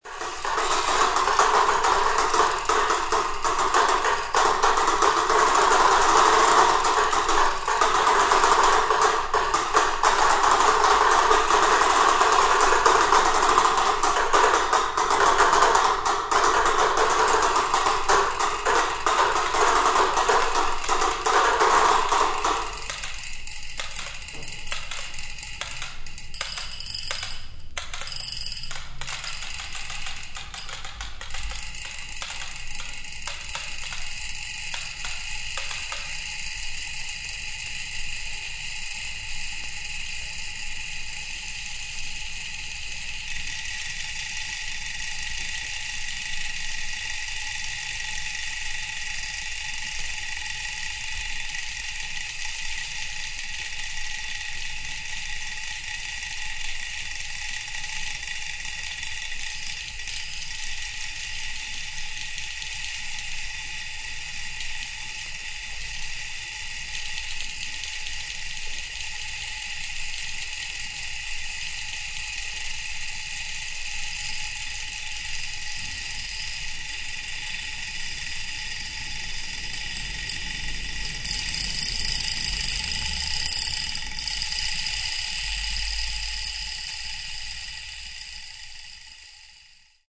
No Audience Side